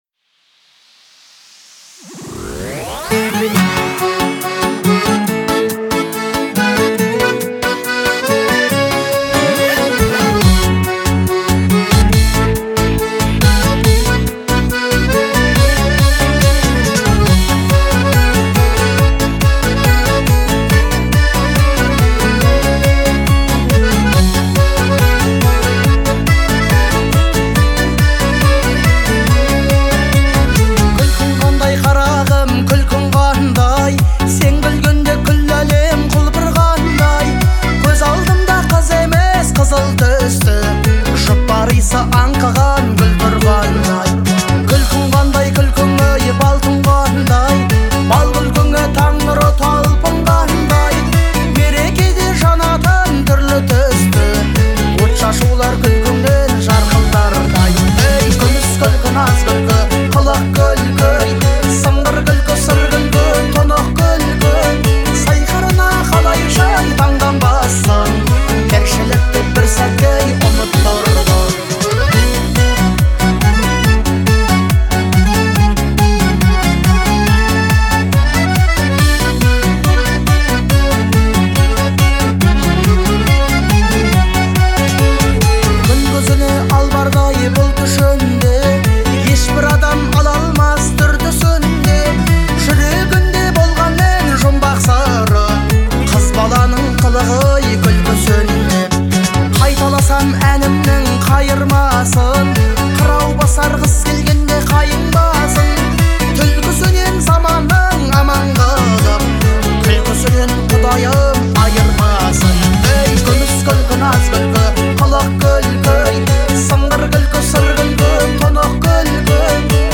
это яркий пример казахской поп-музыки